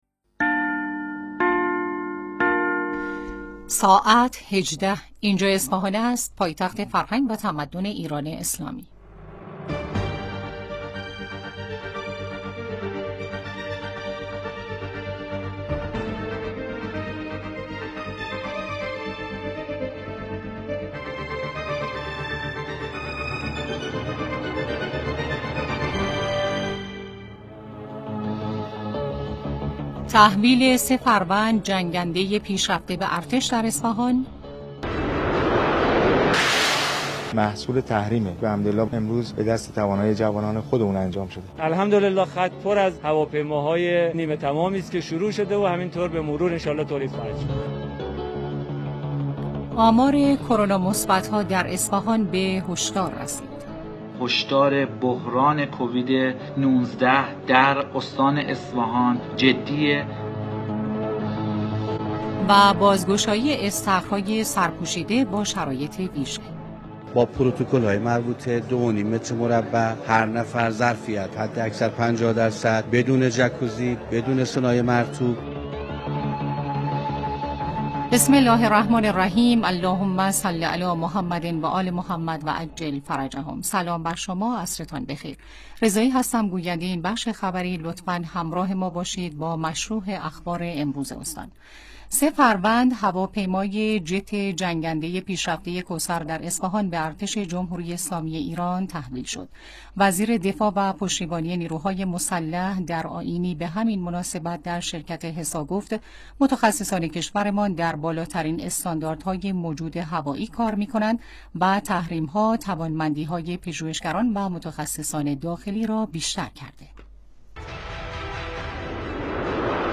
مهمترین رویداد‌های خبری استان اصفهان در پنجم تیر را از بخش خبر عصرگاهی رادیو مرکز اصفهان بشنوید.